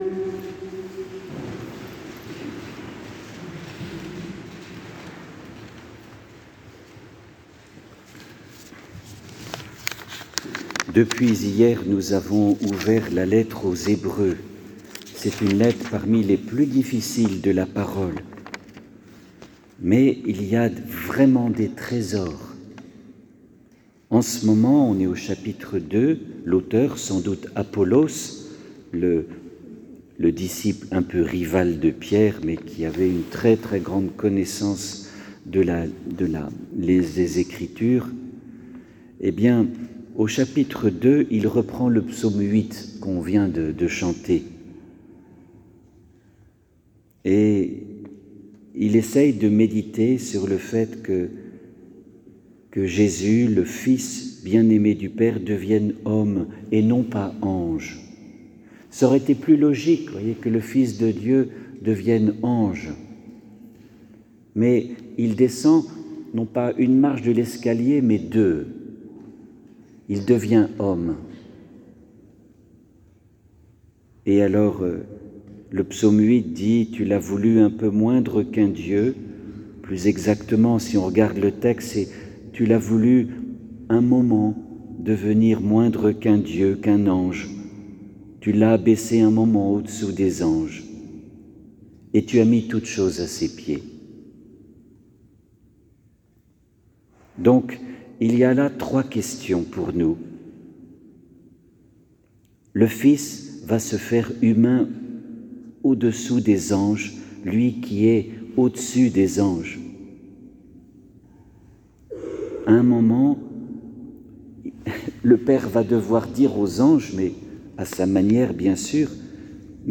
les homélies « Préparons notre Résurrection » – Eglise Saint Ignace